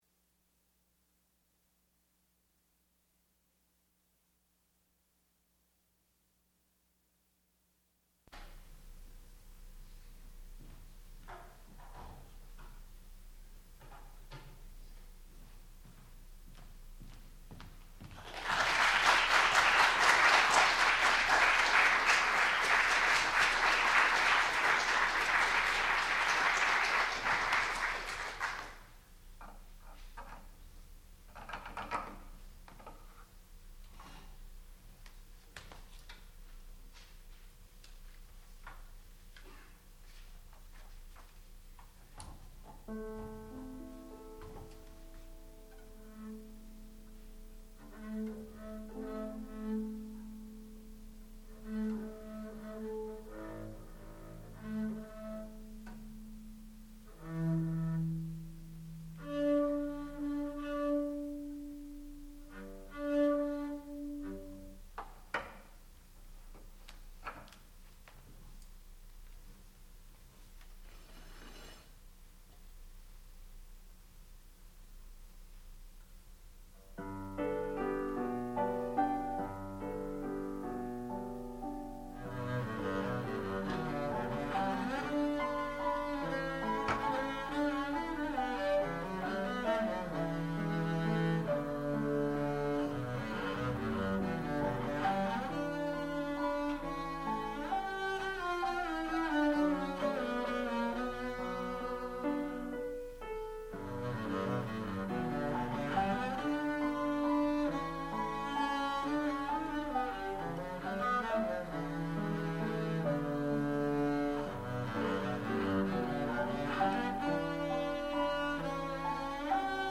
sound recording-musical
classical music
double bass
piano